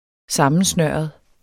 Udtale [ -ˌsnɶˀʌð ]